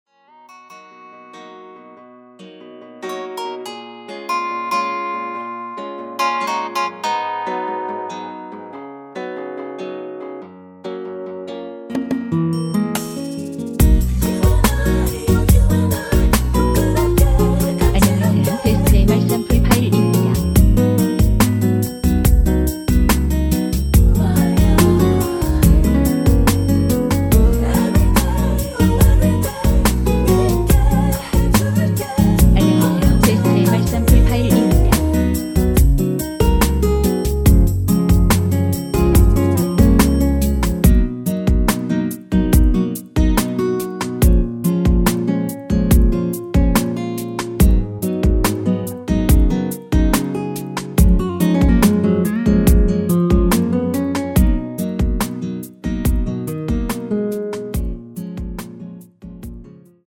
미리듣기에서 나오는 부분이 이곡의 코러스 전부 입니다.(원곡에 코러스가 다른 부분은 없습니다.)
원키에서(-3)내린 코러스 포함된 MR입니다.(미리듣기 확인)
F#
앞부분30초, 뒷부분30초씩 편집해서 올려 드리고 있습니다.